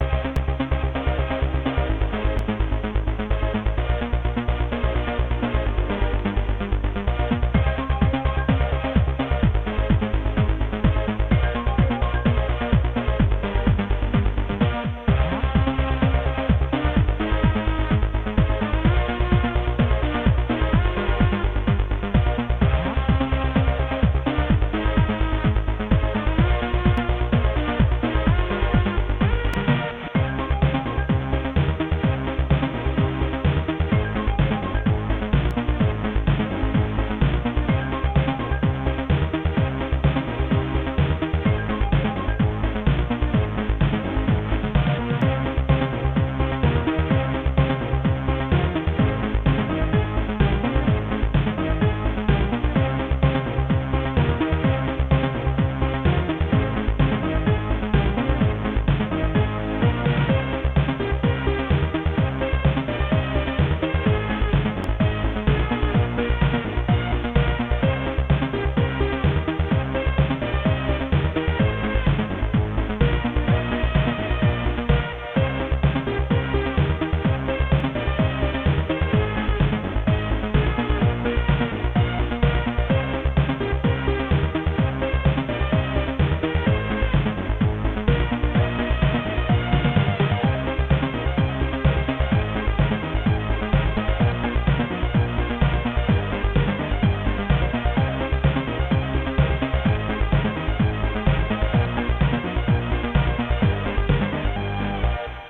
Creative SoundBlaster 16 ct2740
* Some records contain clicks.